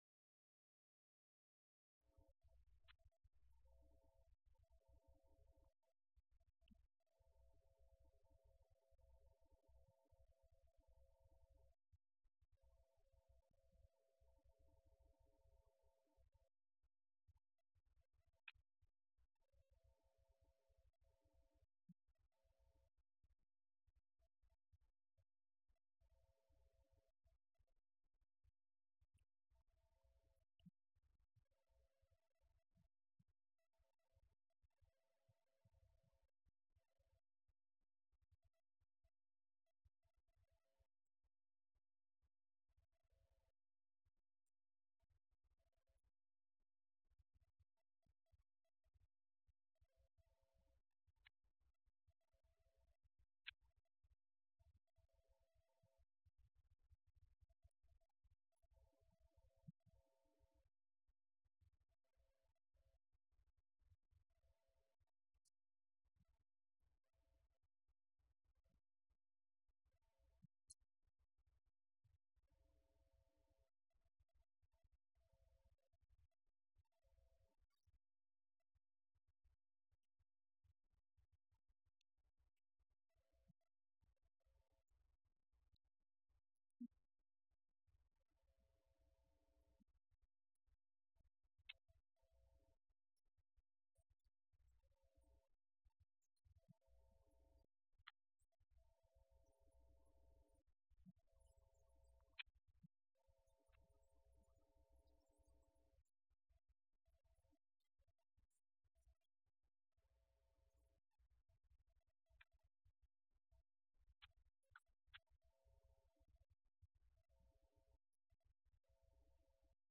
Event: 17th Annual Schertz Lectures
If you would like to order audio or video copies of this lecture, please contact our office and reference asset: 2021Schertz28